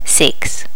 Additional sounds, some clean up but still need to do click removal on the majority.